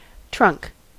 Ääntäminen
US : IPA : [t(ʃ)ɹʌŋk]